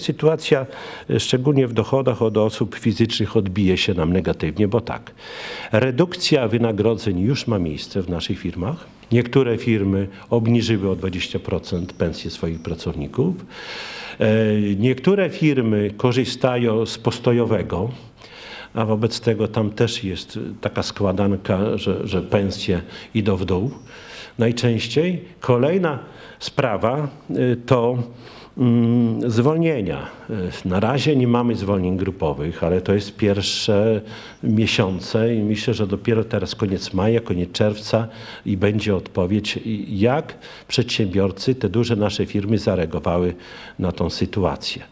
– W związku z tym o konkretach będzie można mówić w lipcu – zapowiedział na ostatniej sesji Rady Miejskiej Czesław Renkiewicz, prezydent Suwałk.